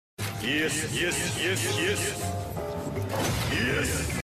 В подборке — культовые фразы, музыкальные моменты и эффекты длиной до 19 секунд.